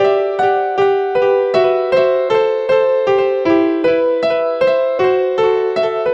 Track 07 - Piano 03.wav